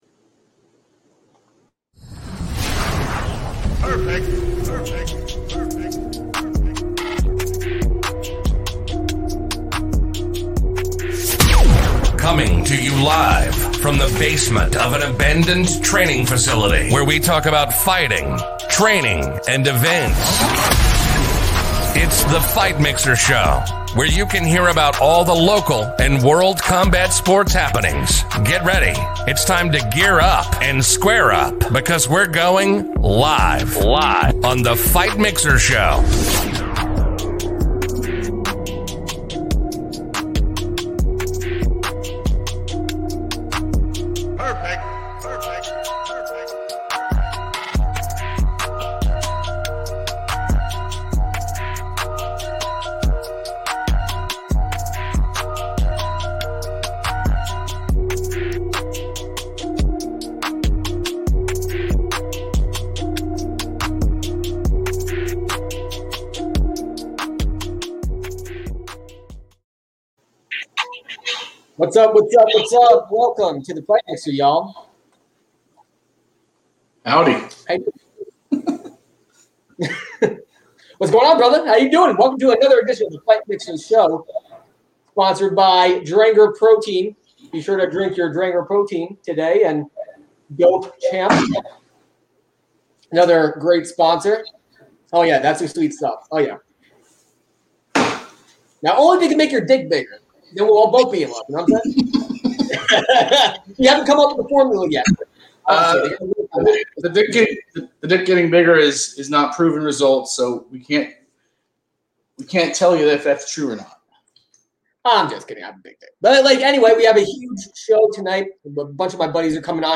Interview - Fight Mixer